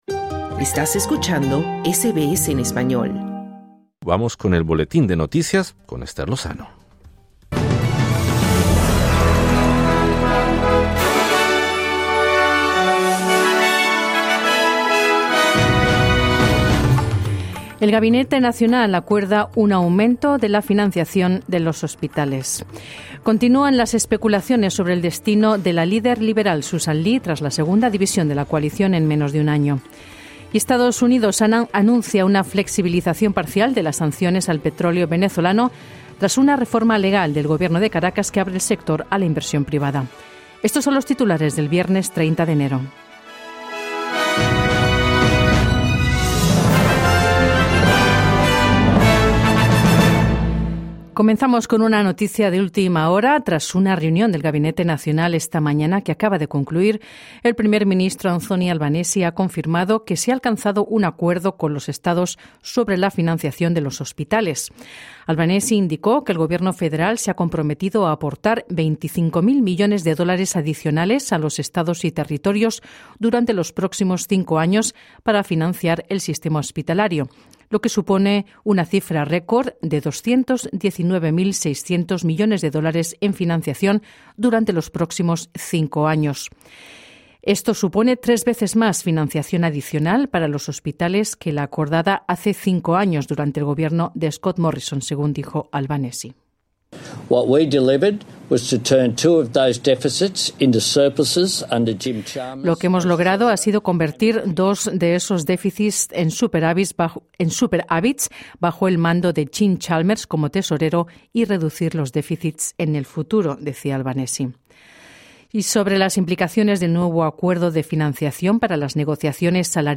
Boletín de noticias viernes 30/01/2026: El gabinete nacional acuerda aumentar la financiación de los hospitales en 219,600 millones durante los próximos cinco años. Continúan las especulaciones sobre el destino de Sussan Ley, tras la segunda división de la Coalición en menos de un año.